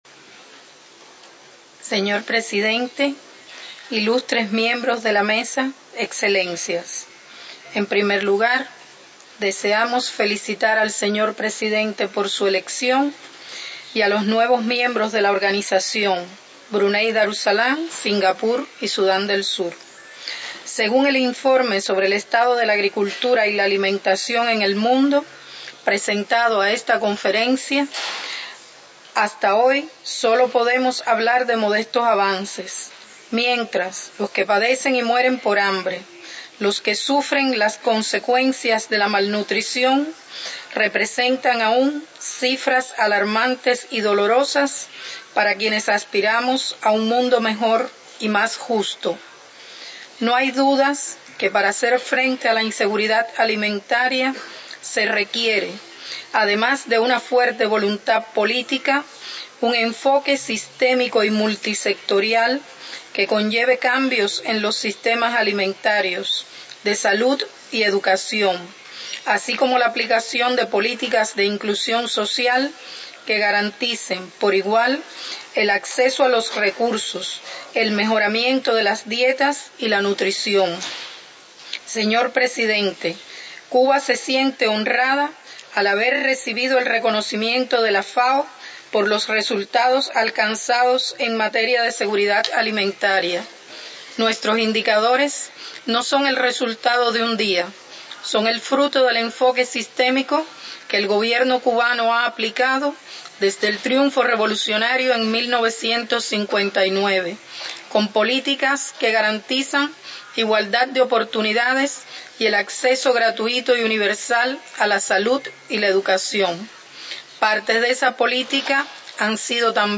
FAO Conference
Statements by Heads of Delegations under Item 9:
Her Excellency Milagros Carina Soto Agüero Ambassador Permanent Representative of Cuba to FAO